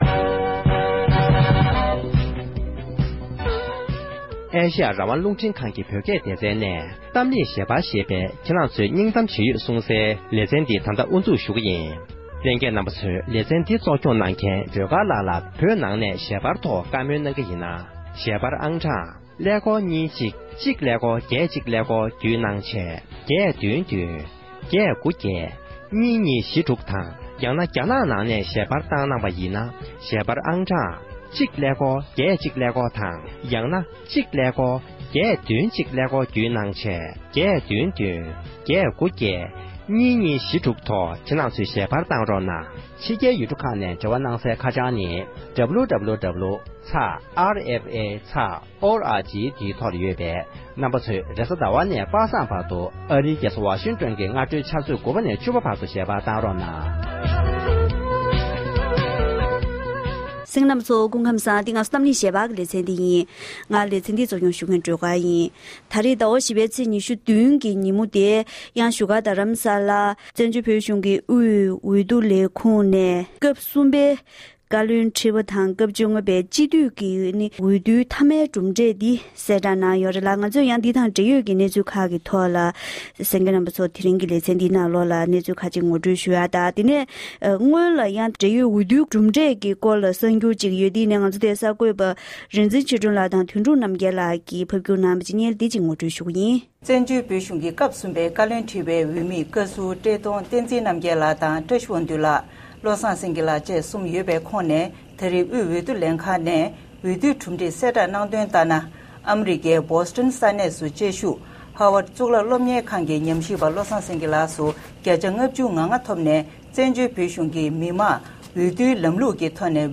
༄༅༎དེ་རིང་གི་གཏམ་གླེང་ཞལ་པར་ལེ་ཚན་ནང་དུ